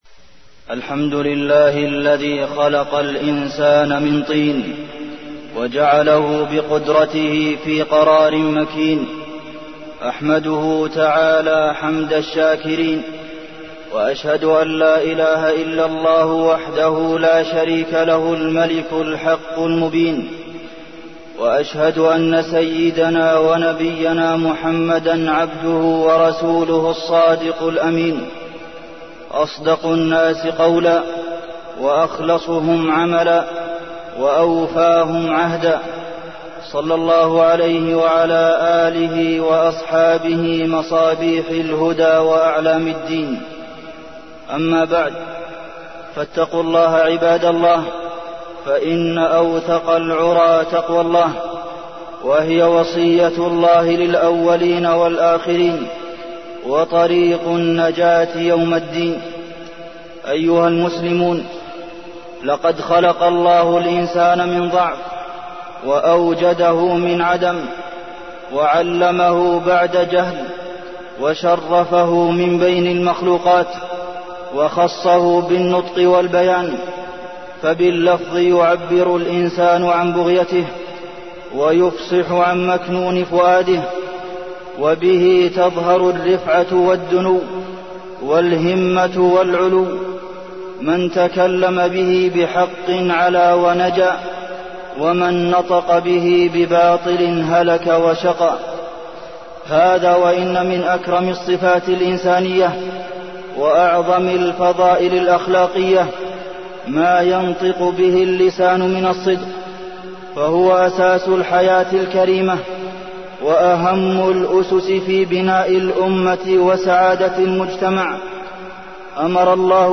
تاريخ النشر ١٦ ربيع الأول ١٤١٩ هـ المكان: المسجد النبوي الشيخ: فضيلة الشيخ د. عبدالمحسن بن محمد القاسم فضيلة الشيخ د. عبدالمحسن بن محمد القاسم الصدق